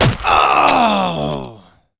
HIT03.WAV